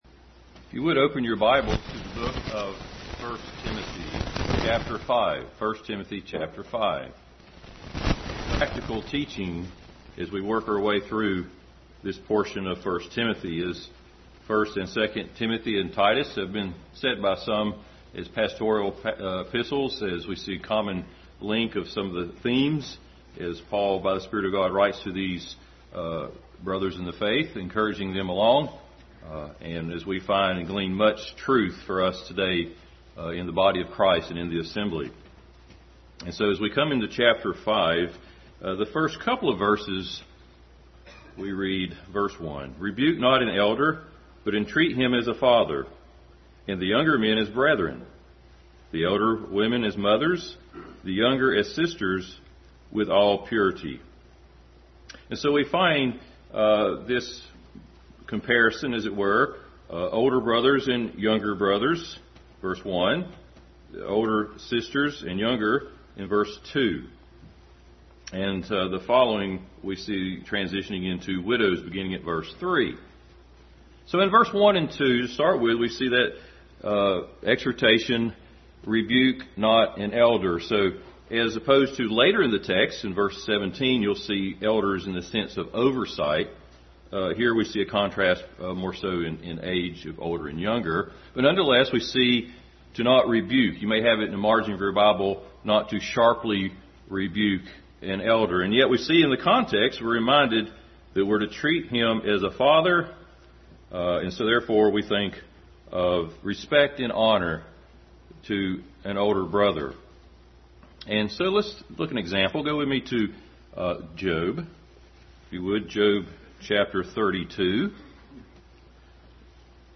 18:1-8 Service Type: Sunday School Adult Sunday School continued study in 1 Timothy.